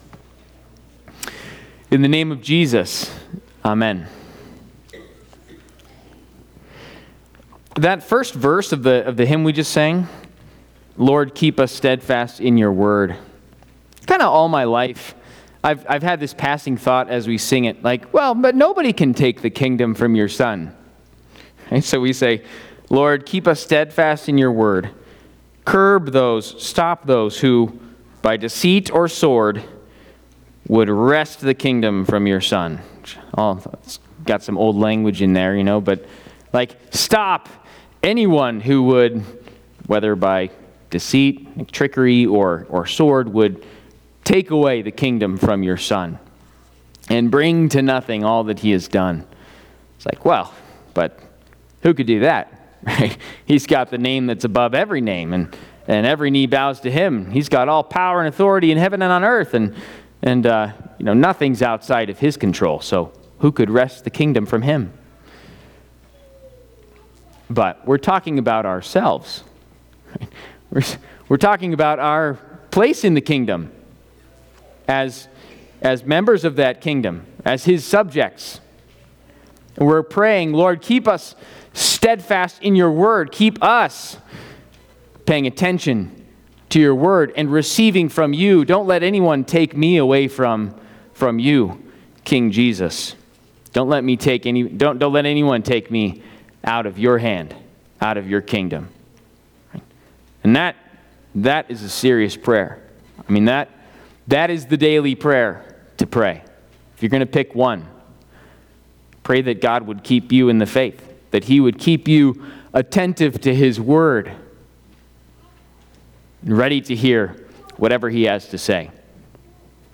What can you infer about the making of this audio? Trinity Lutheran Church, Greeley, Colorado Is it a Surprising Message?